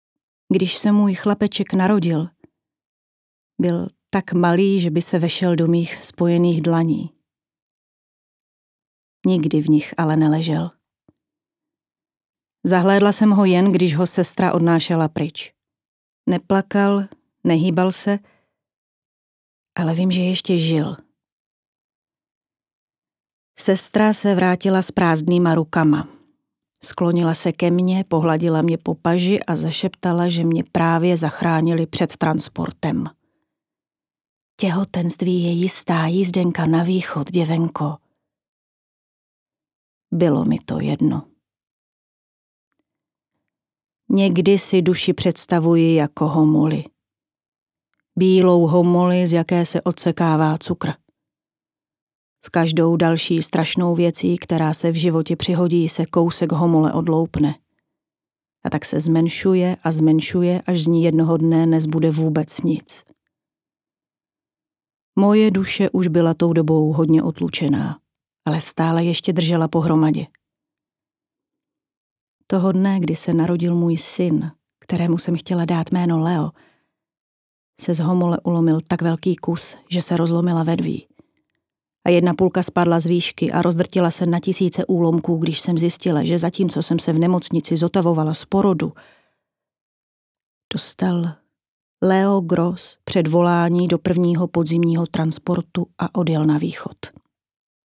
Audio kniha: